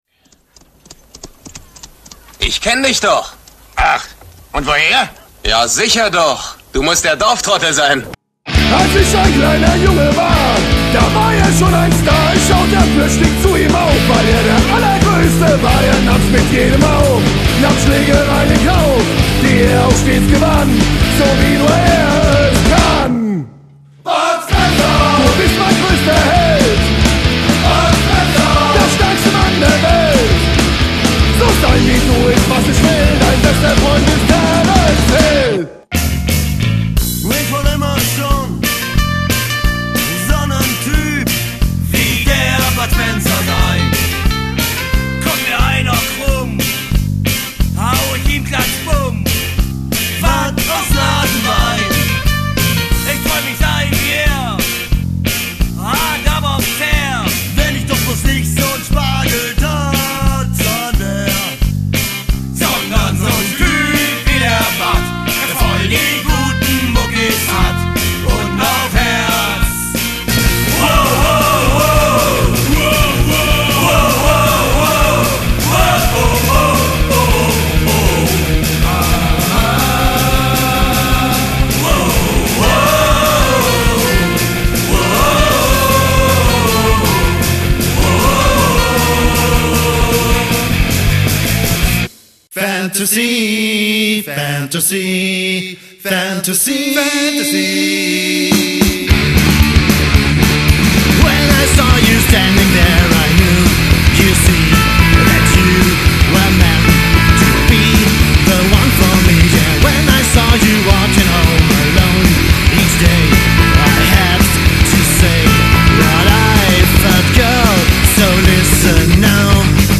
various artists of ska,punk,oi & rock!